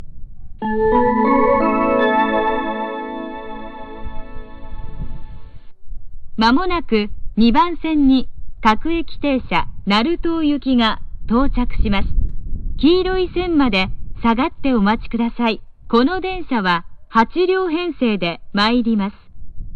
発車メロディー
綺麗に鳴ってくれました。
･音質：良